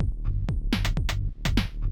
• lo-fi house - techno 124 - D.wav